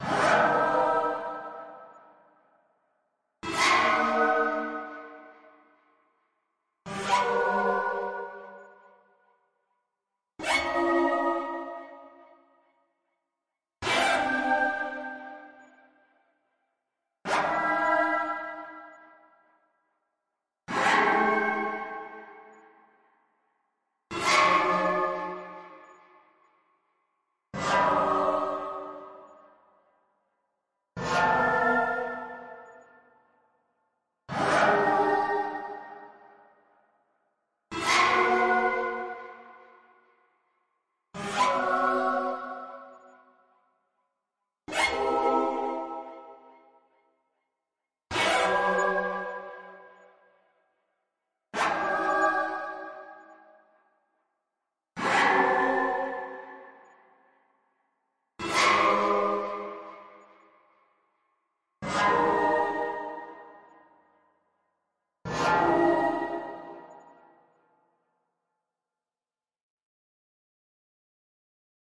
描述：当一些魔法完全出错，在一个巨大的史诗般的失败中倒下时的声音。